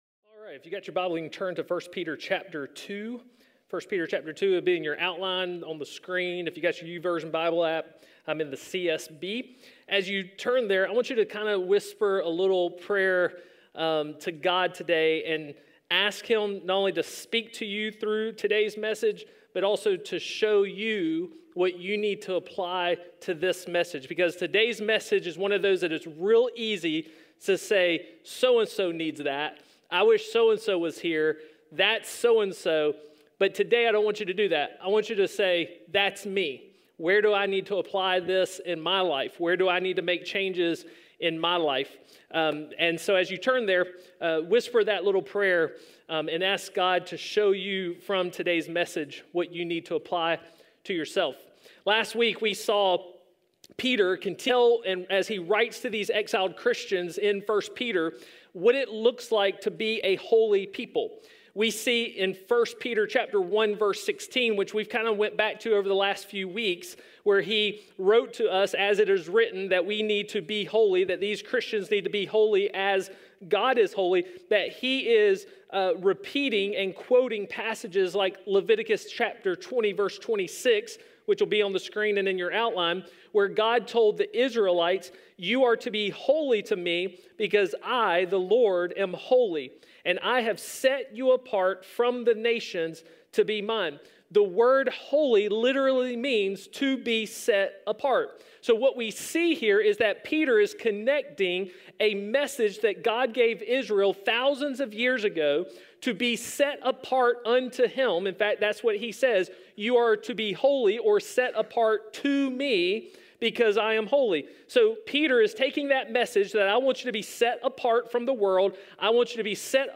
A message from the series "Foreigners."